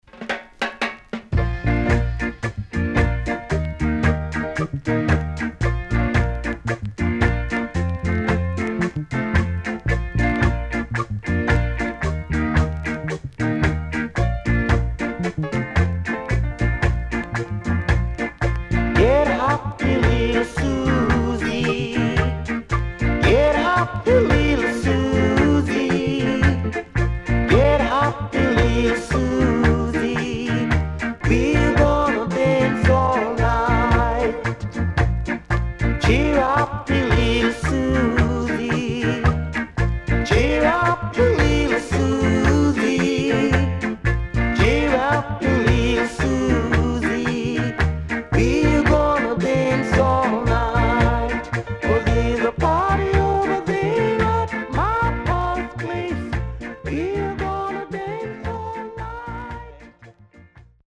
Rare.70年 W-Side Good Early Reggae
SIDE A:所々チリノイズ入ります。